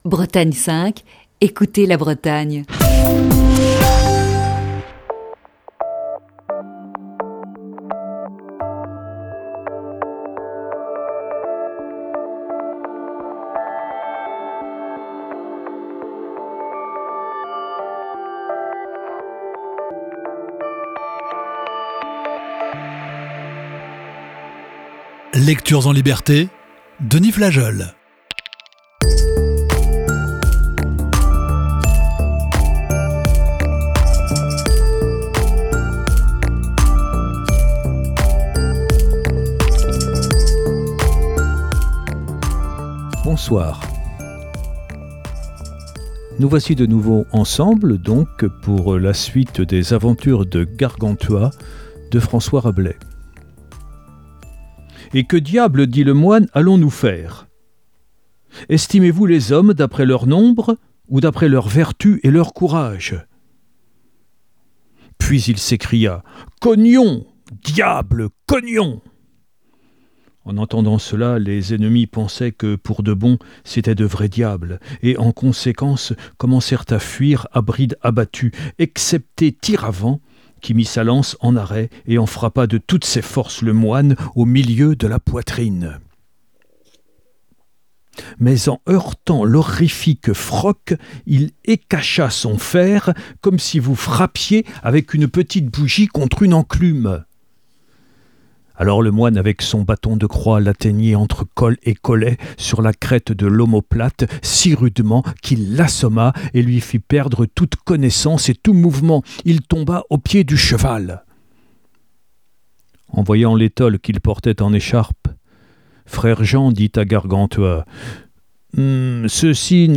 Voici ce soir, la lecture de la huitième partie de ce récit.